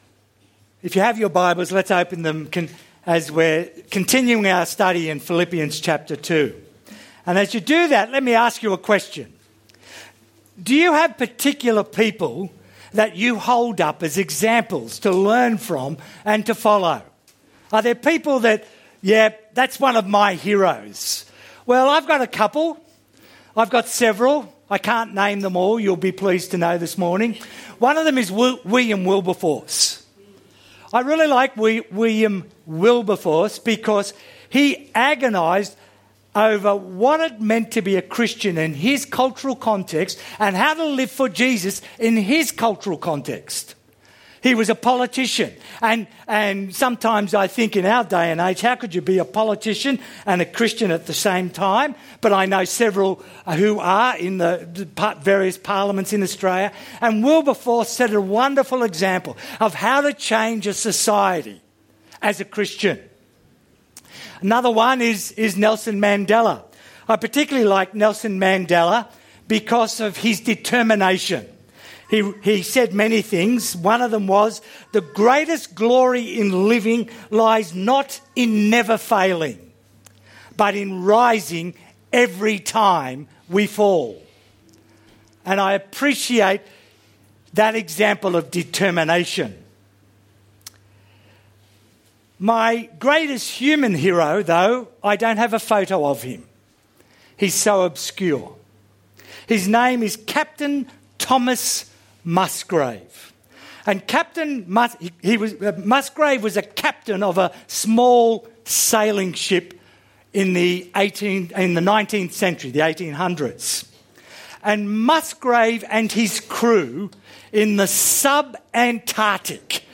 Categories Sermon Tags 2015